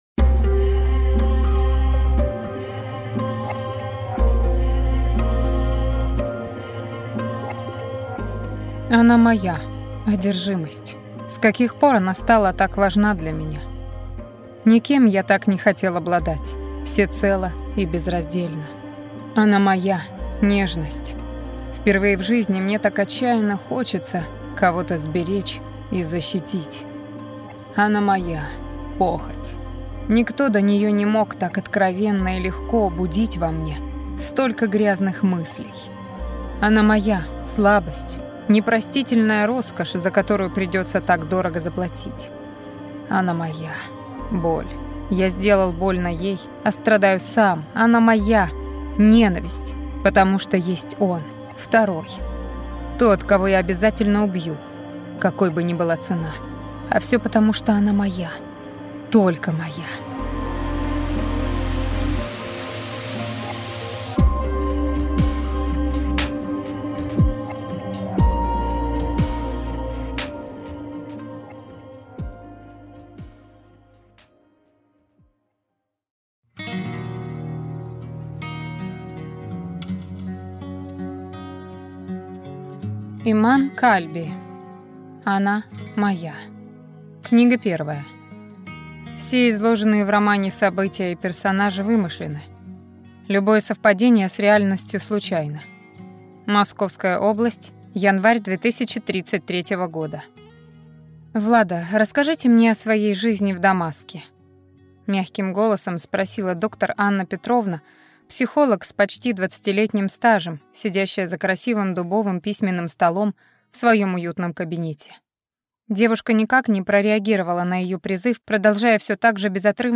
Аудиокнига Она моя…